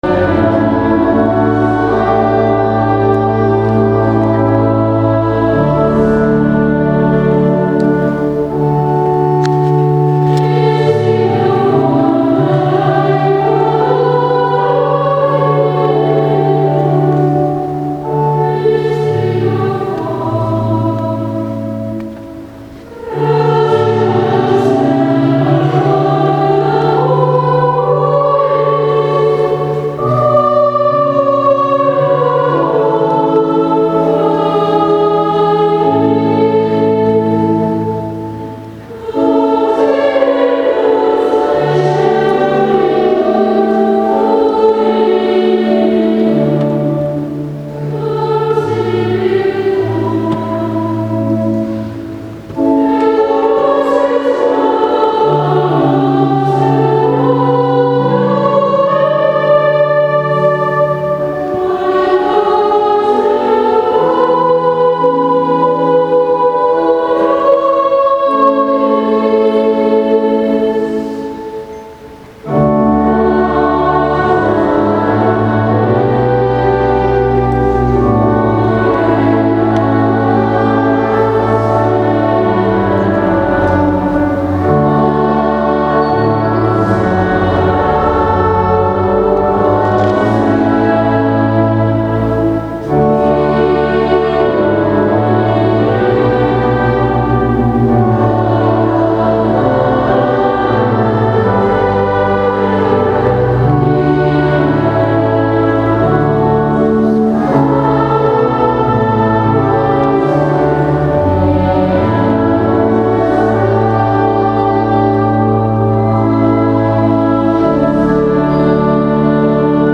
Ежедневно в час дня в исполнении хора мальчиков, обучающихся в музыкальной школе при монастыре «Escolania de Montserrat», в храме звучит гимн деве Марии - известное в Каталонии песнопение «Виролай» (лат.
запись этого выступления (качество не высокое).